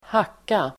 Uttal: [²h'ak:a]